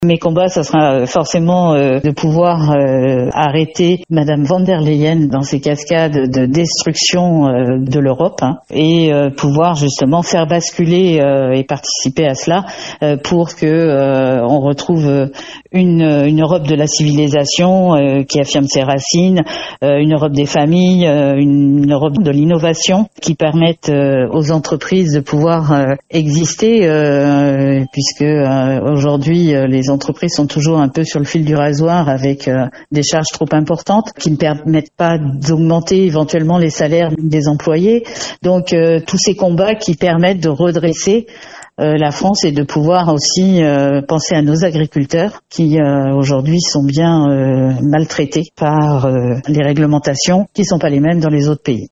Élections européennes 2024. Entretien